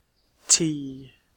Uttal
IPA : /ˈti/